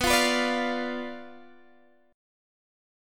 Bdim Chord
Listen to Bdim strummed